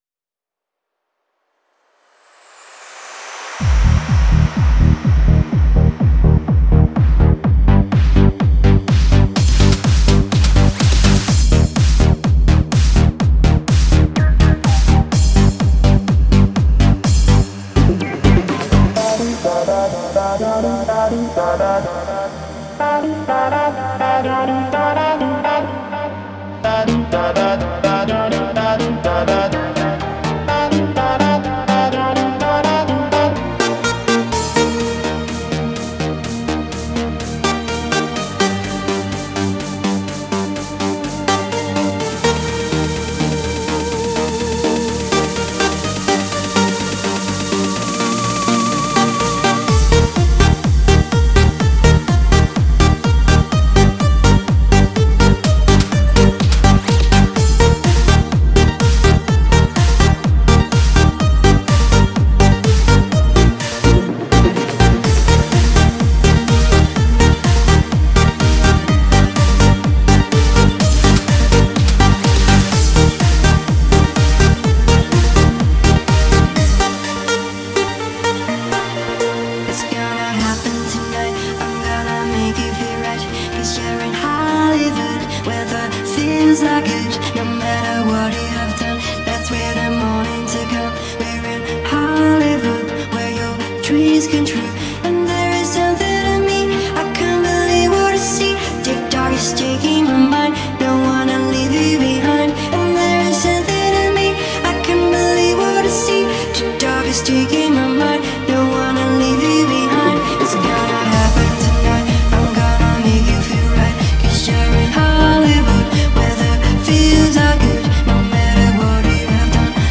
это зажигательный трек в жанре дэнс и электроника
Песня наполнена энергичными ритмами и мелодиями